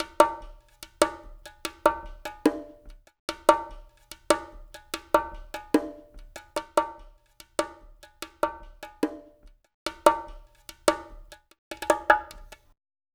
BOL BONGOS.wav